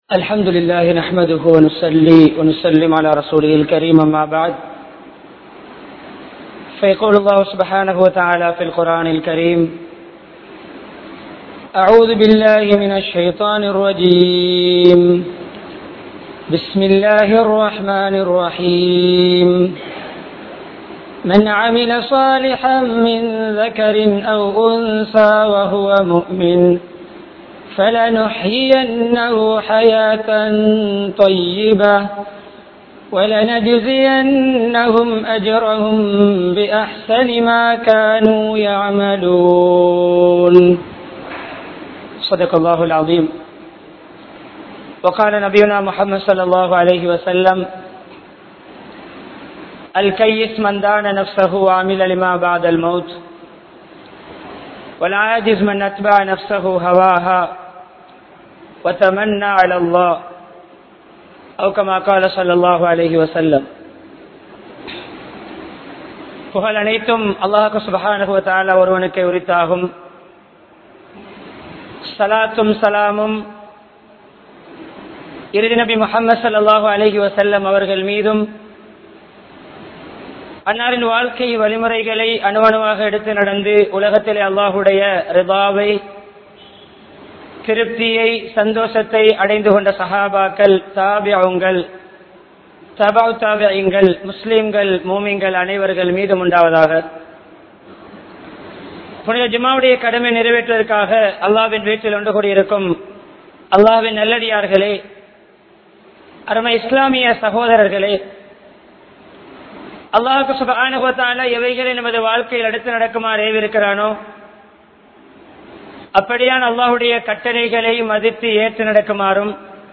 Marumaithaan Niranthara Vaalkai (மறுமைதான் நிரந்தர வாழ்க்கை) | Audio Bayans | All Ceylon Muslim Youth Community | Addalaichenai
PachchaPalli Jumua Masjidh